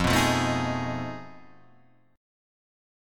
F7#9b5 Chord